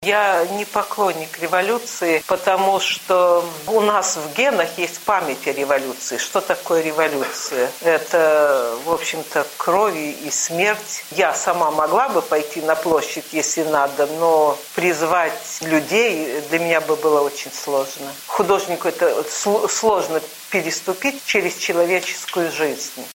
Сустрэча зь пісьменьніцай адбылася ў Нацыянальным фондзе за дэмакратыю (NED).
NED i Радыё Свабода вялі жывую трансьляцыю зь мерапрыемства.